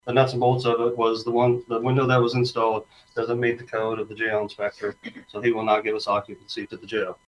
Supervisor Mike Dickson provided the board with a brief rundown on what is going on with the glass at the new law enforcement center.